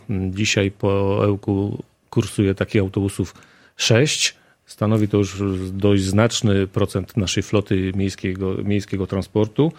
Mirosław Hołubowicz – zastępca prezydenta Ełku.